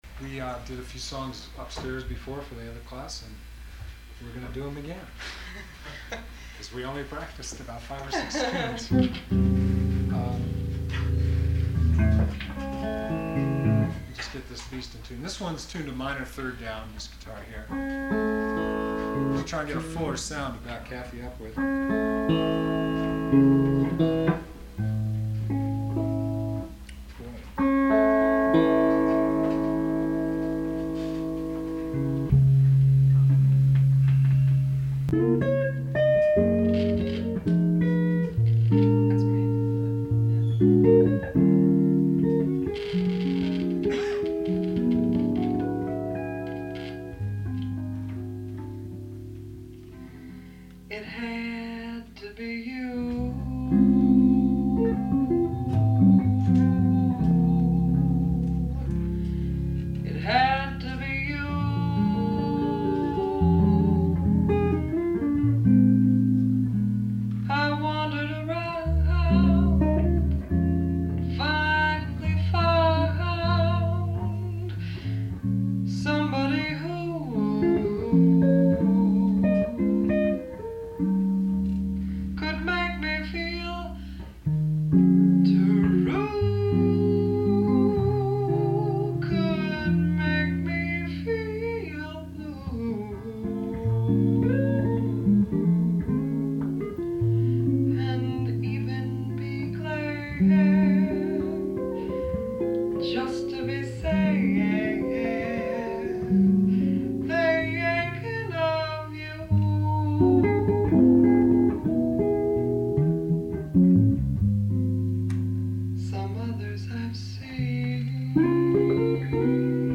Ted Greene "Guitar Institute of Technology" Seminar - 1978